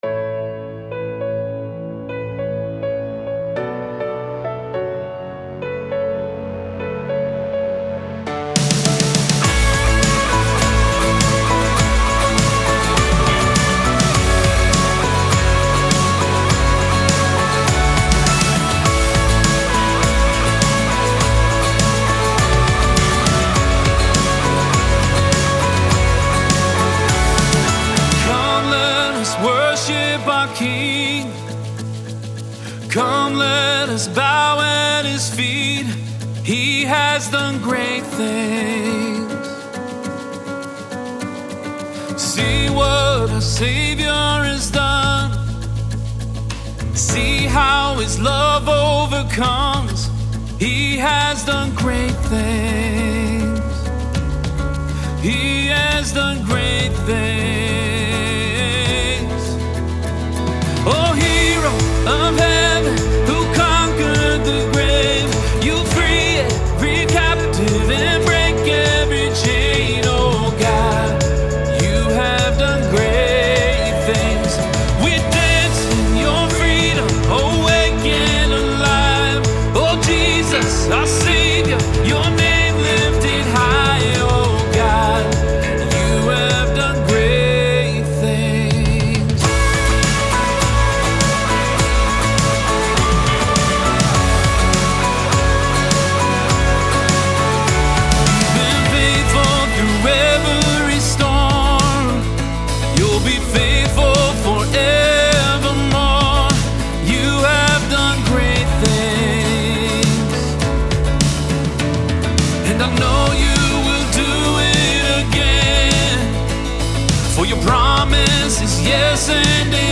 Full arrangement demo
• Keys: A, G, and Bb
• Tempo: 102 bpm, 4/4 time
STYLE: ORGANIC
• Drums
• Percussion
• Acoustic Guitar
• Bass
• Electric Guitars (2 tracks)
• Synths
• Pads
• Piano (2 tracks)